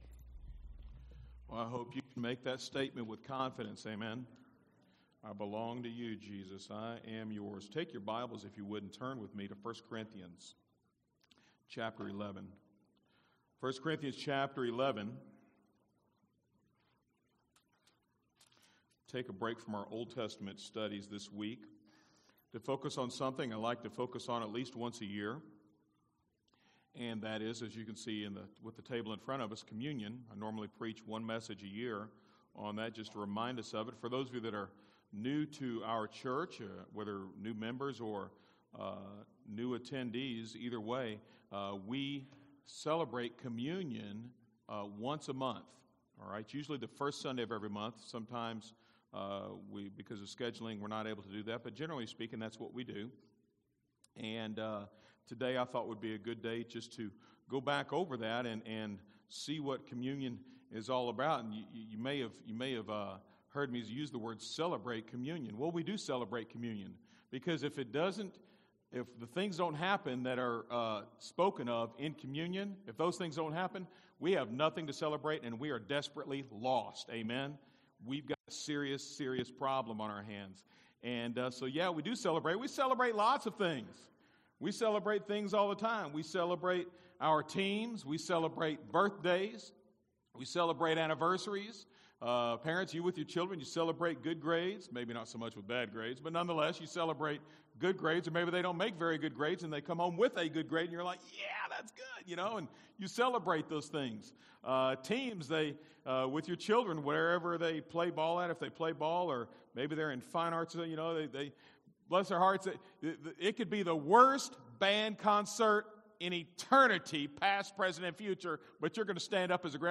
From Series: "Individual Sermons"